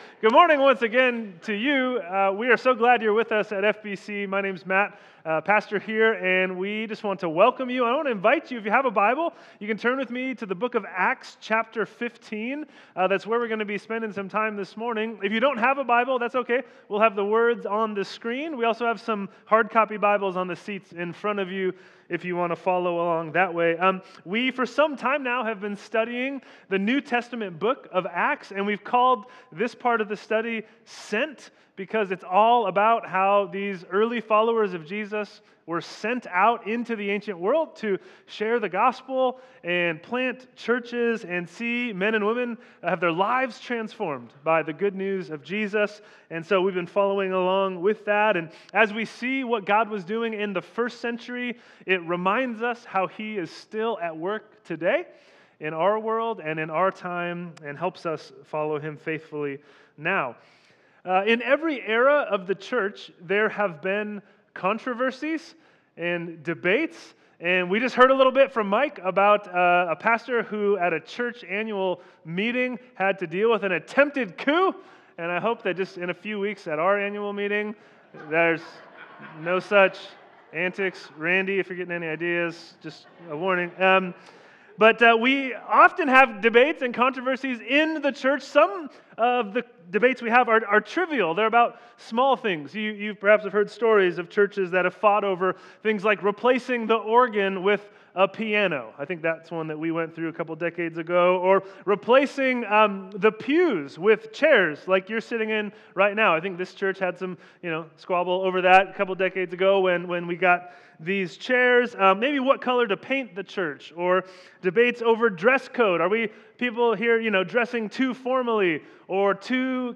Sermons | First Baptist Church of Benicia